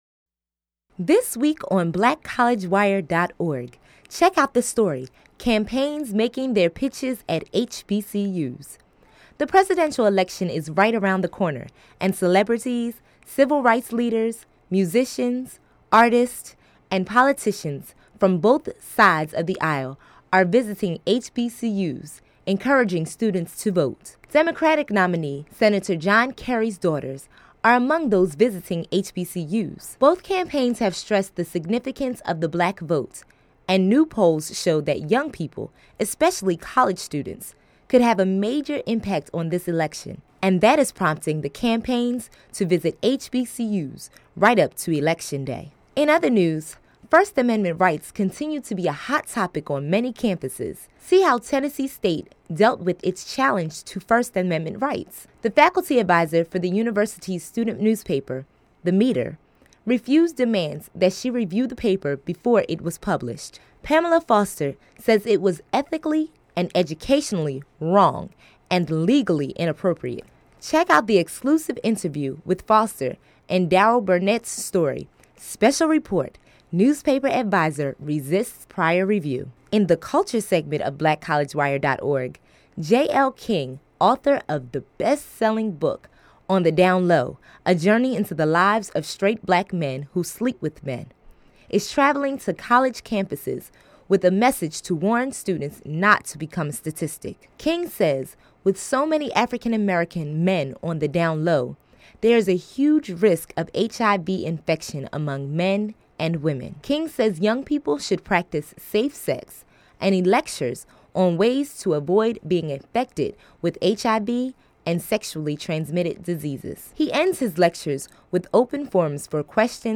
News Summary of the Week